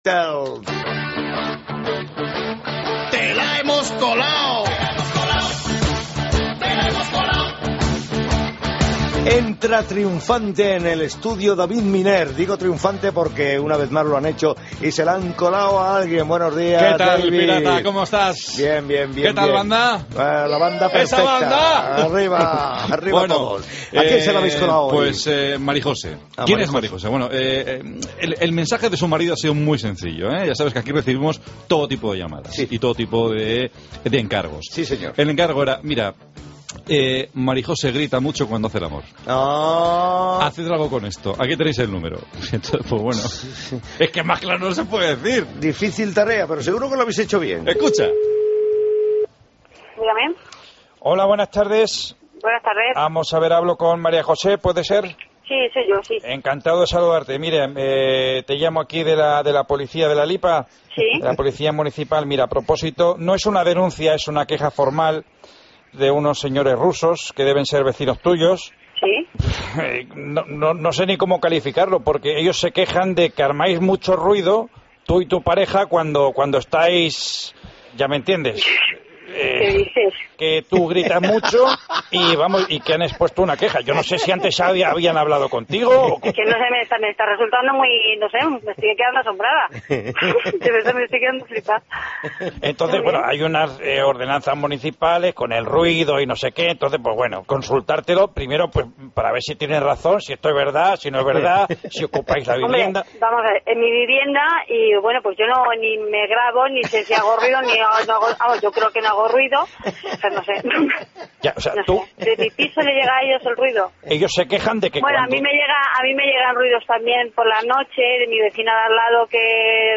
Llamada broma gritos en la cama